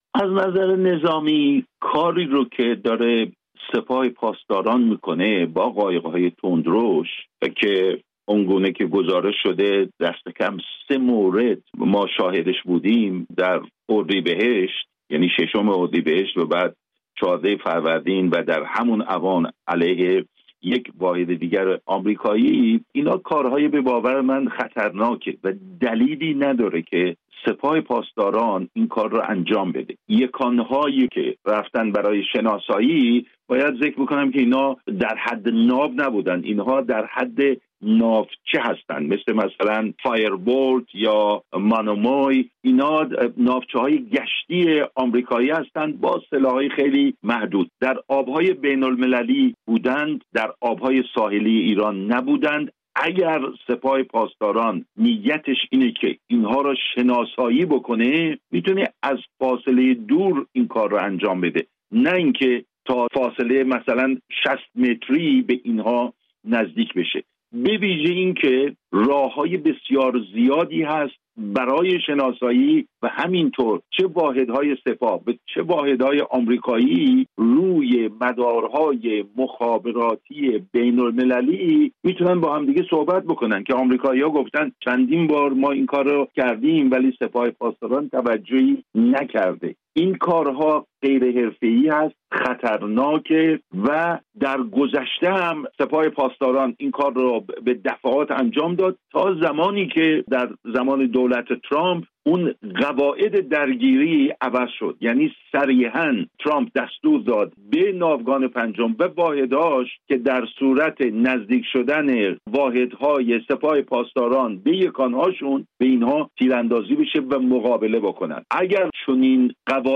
کارشناس نظامی